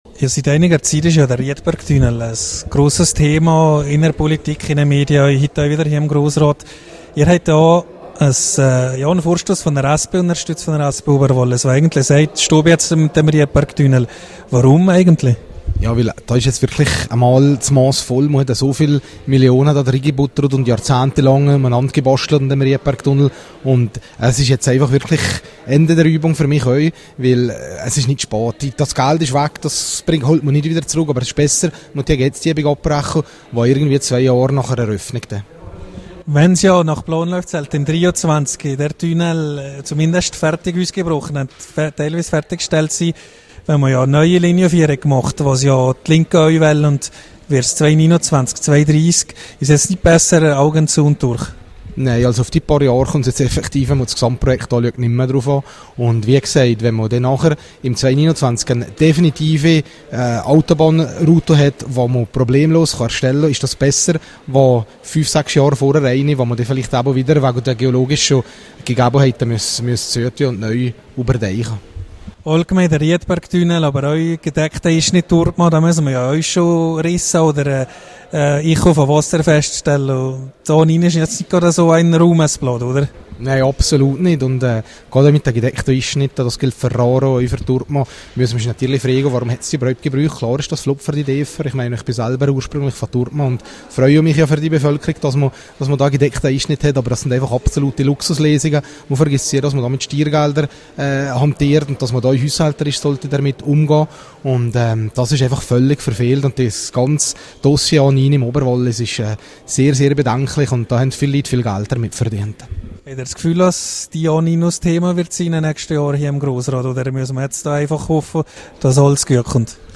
Wasserzinsen: Interview mit Finanzminister Roberto Schmidt.